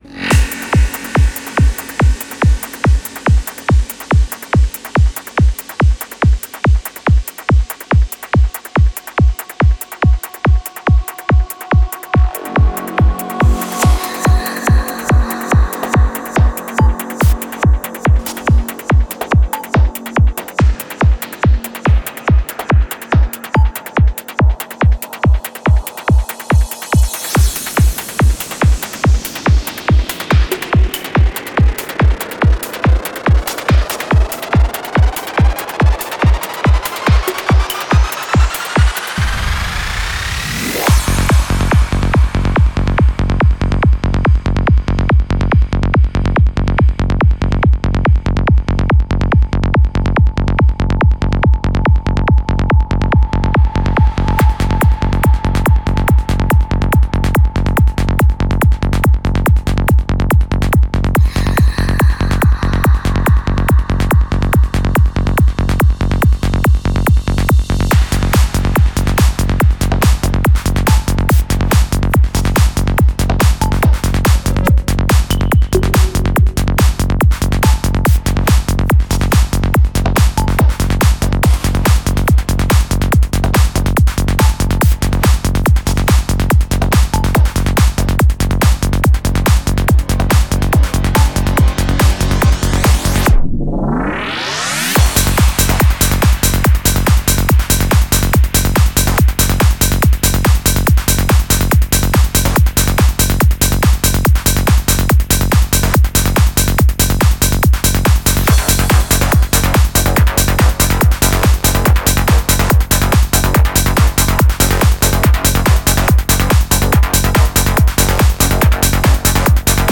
Style: FullOn, Pop
Quality: avg. 250kbps / Joint Stereo